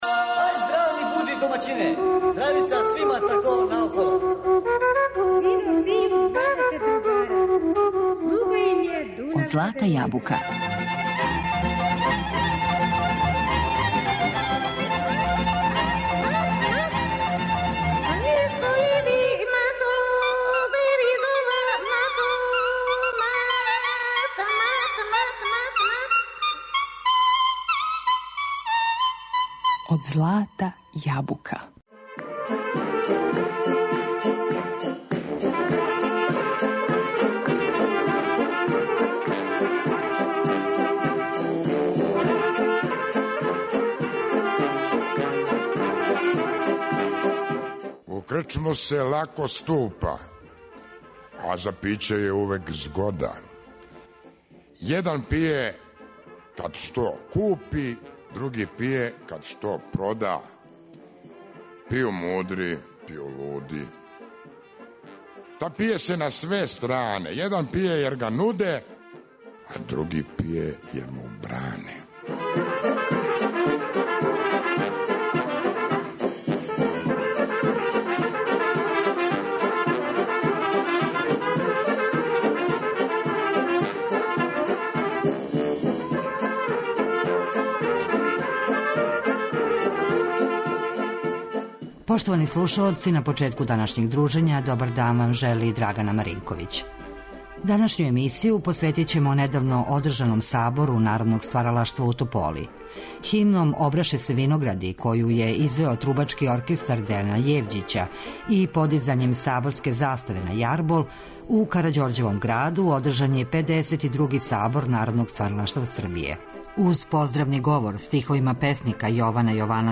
У емисији ћемо чути и документарне снимке са овогодишње манифестације.